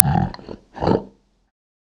PixelPerfectionCE/assets/minecraft/sounds/mob/polarbear/idle2.ogg at mc116